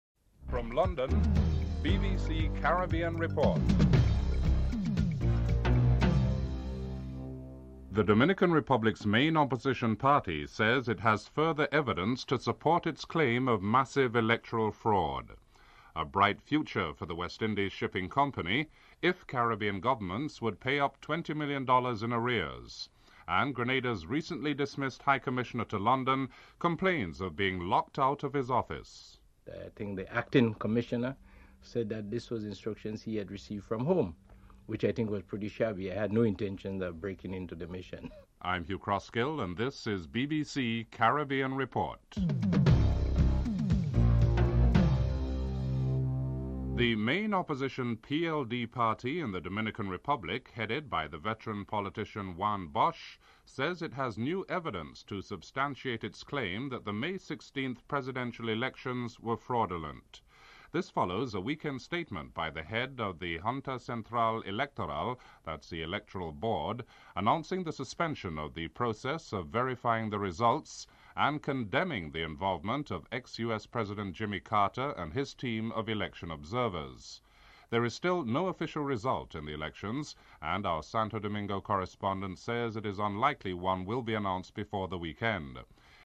1. Headlines (00:00-00:45)
3. Financial News.